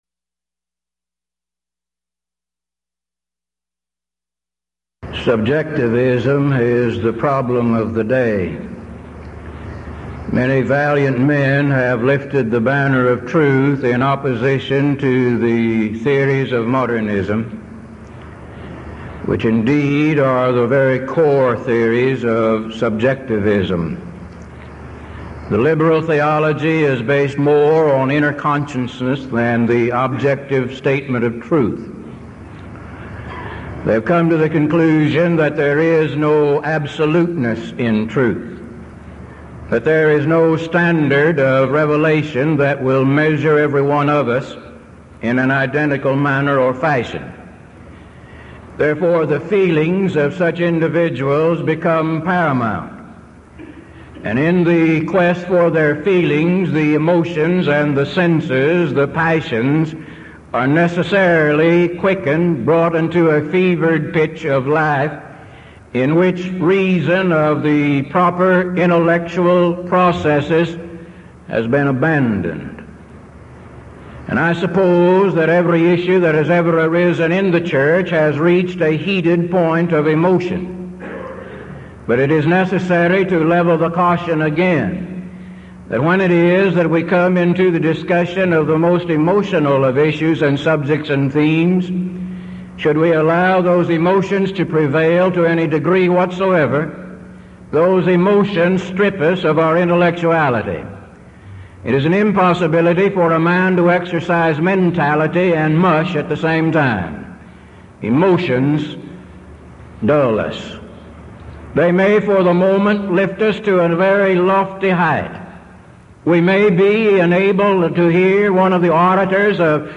Event: 1982 Denton Lectures